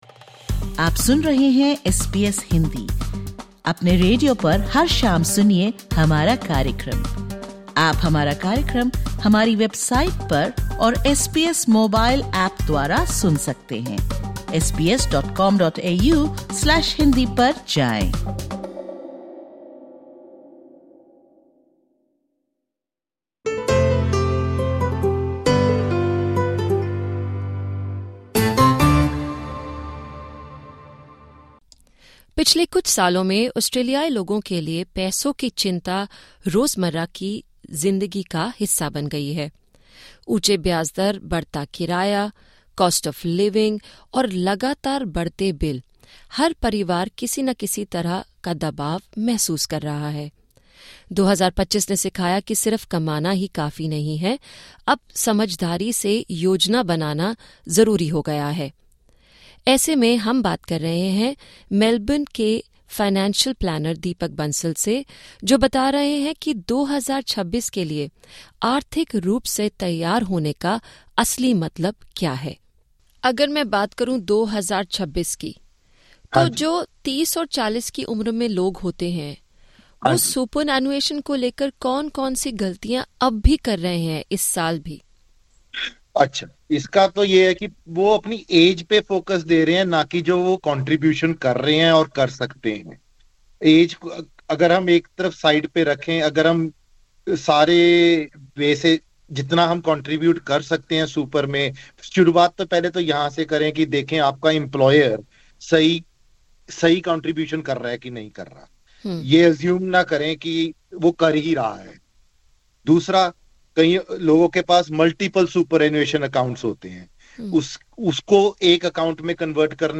The views/opinions expressed in this interview are the personal views of the individual.